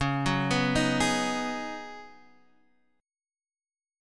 Db9sus4 chord